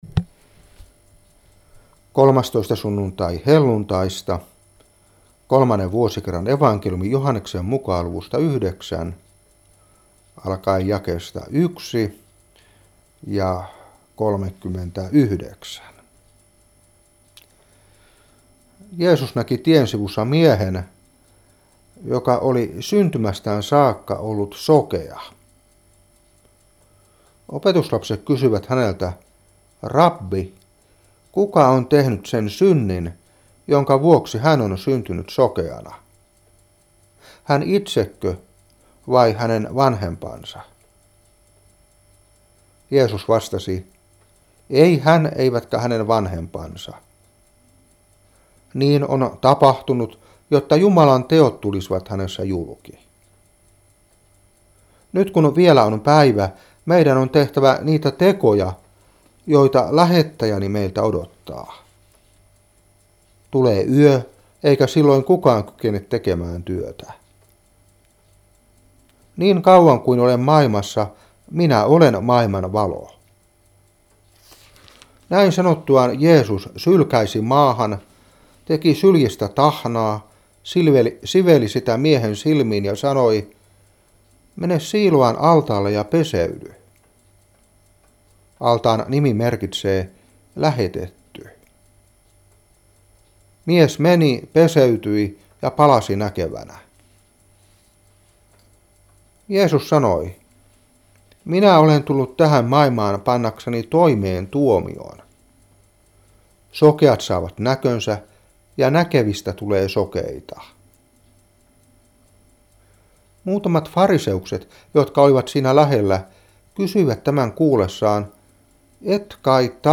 Saarna 2015-8. Joh.9:1-7, 39-41.